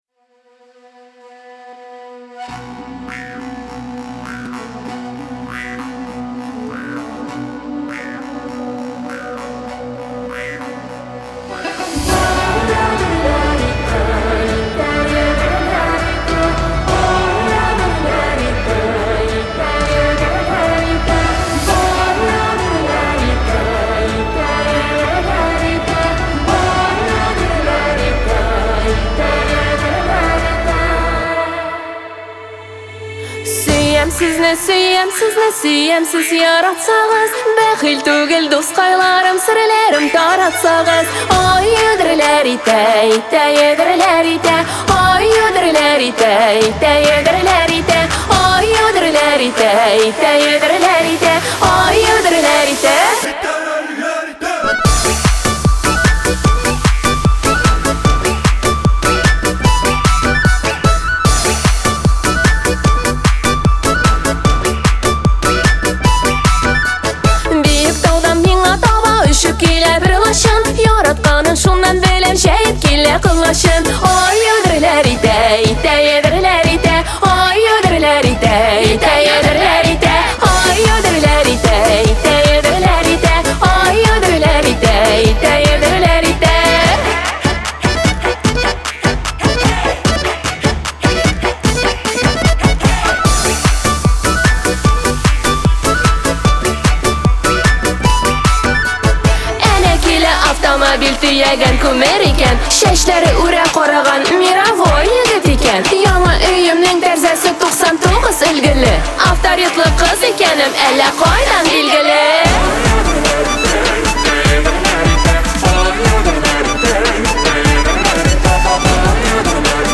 Жанр: Поп, Этно-поп